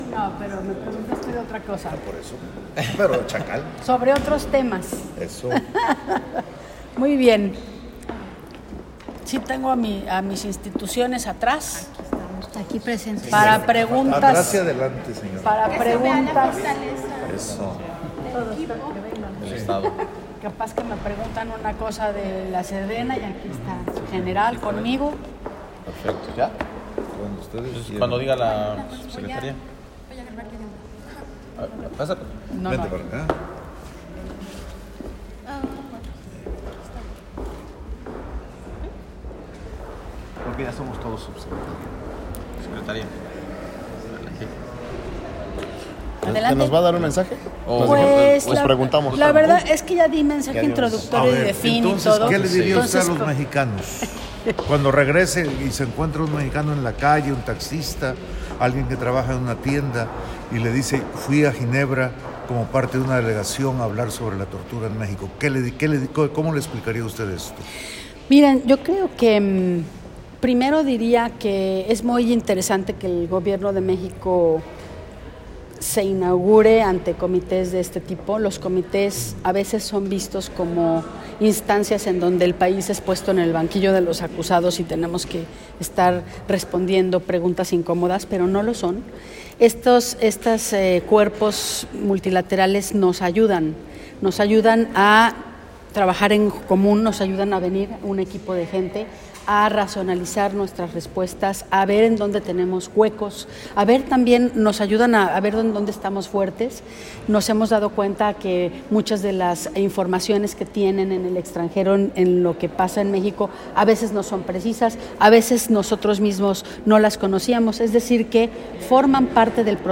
Y aquí una parte de una entrevista que di sobre el tema.